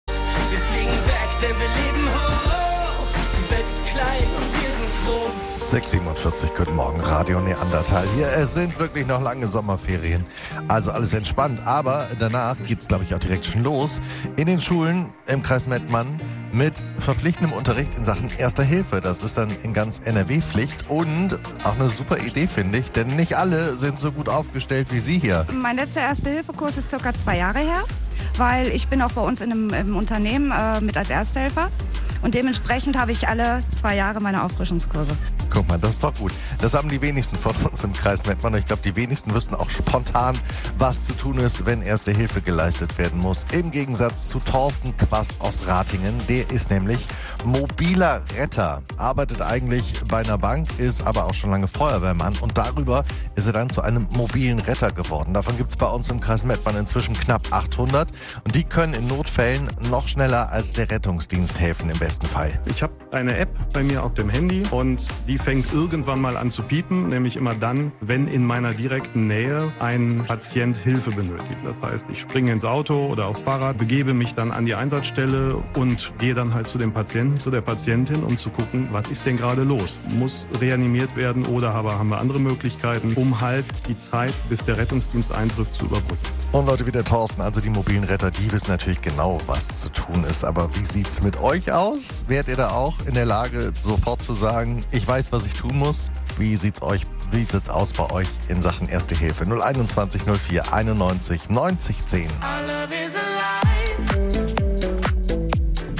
Wir haben mit einem Mobilen Retter gesprochen und Interessantes erfahren.